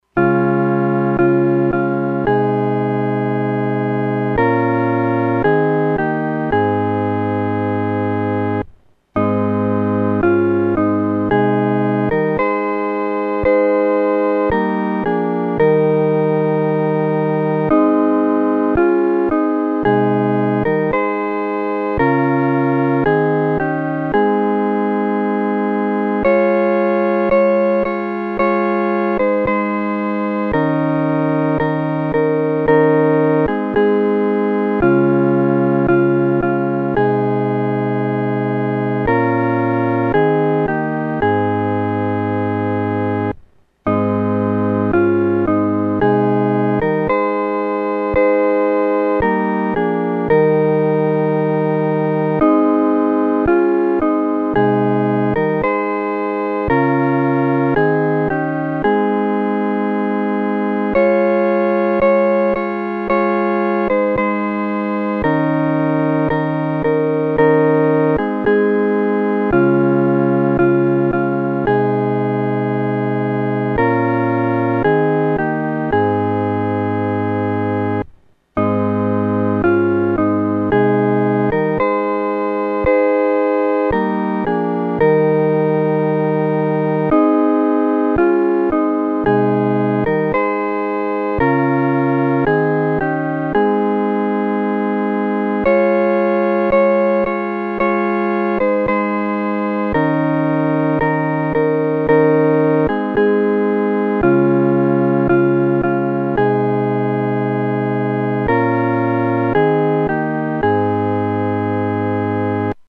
合奏（四声部）